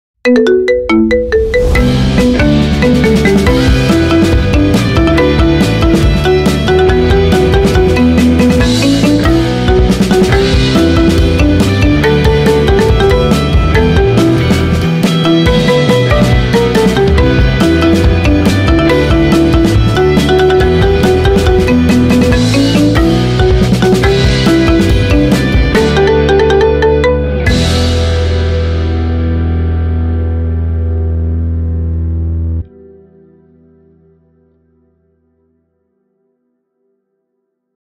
Kategoria Marimba Remix